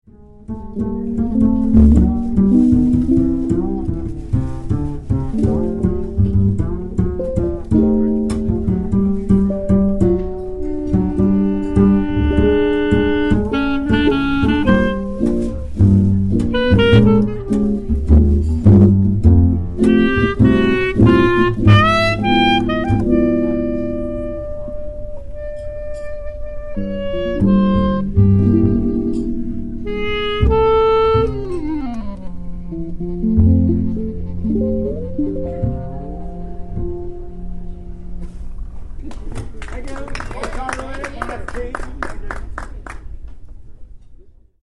Songs from Vegas
They had an awesome three piece jazz trio;
clarinet, guitar and base.
jazztrio.mp3